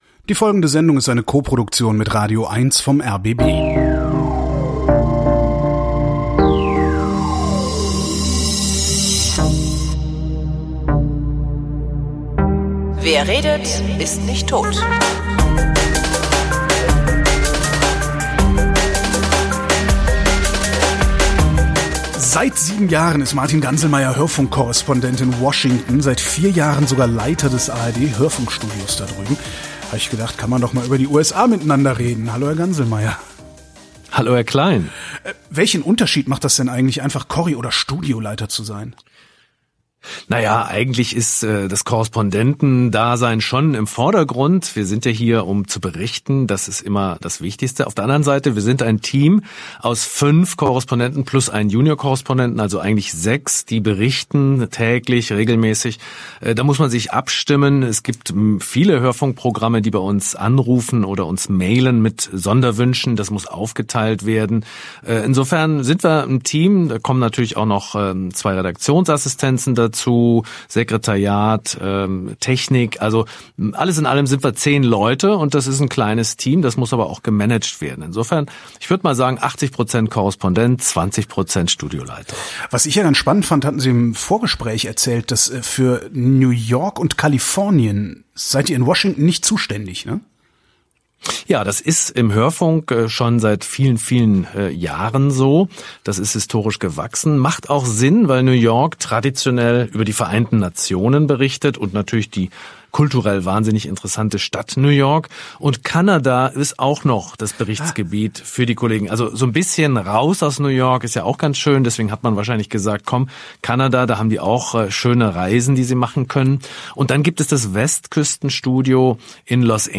Da hab ich auf die Schnelle eine Leitung in die USA bestellt und ein Stündchen mit ihm gequatscht.